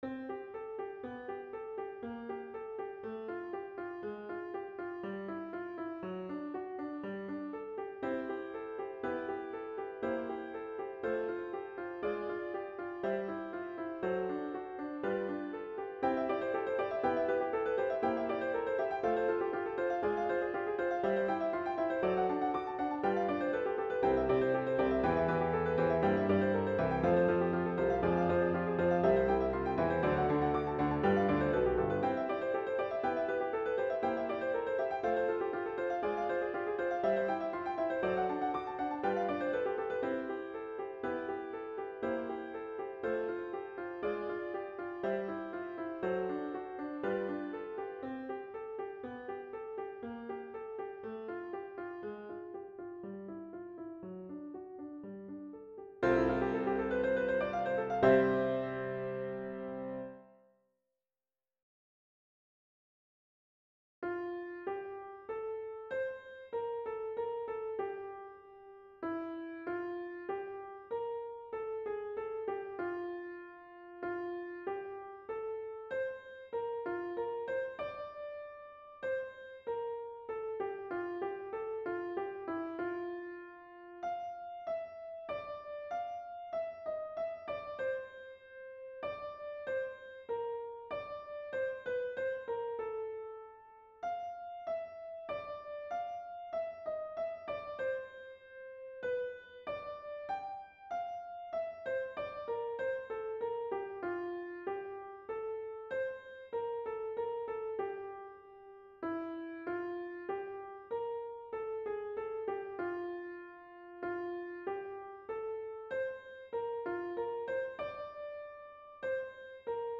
This is a combination of three piano works I made up over the last few days.